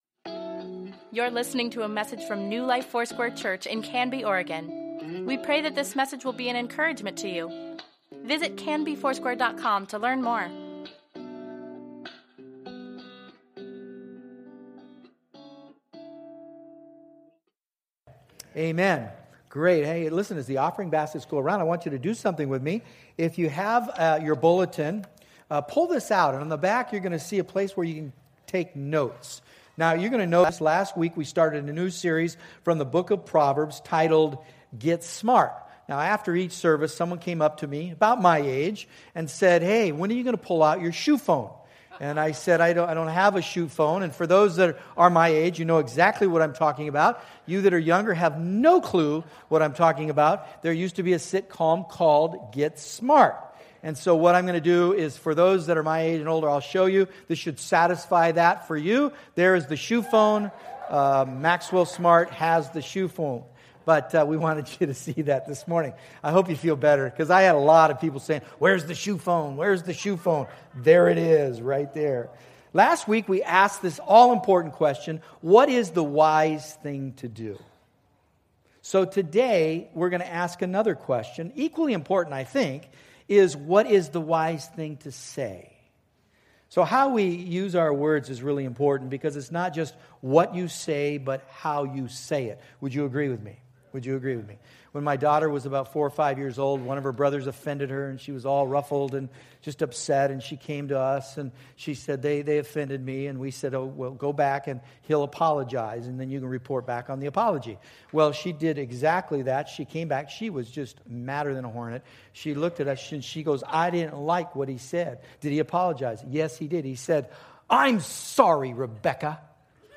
Weekly Email Water Baptism Prayer Events Sermons Give Care for Carus GET SMART: Speech April 30, 2017 The words we speak are more important than we might think.